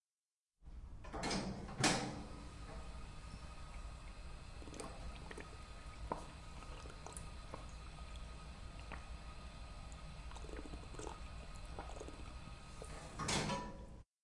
描述：这个声音是在庞培法布拉大学（巴塞罗那）Campus de la Comunicació一楼的Roc Boronat大楼里用Zoom H2录音机录制的。 我们所感受到的是在喷泉中喝水所产生的声音，以及水是如何落在金属喷泉上的
Tag: 校园UPF 喷泉 UPF-CS13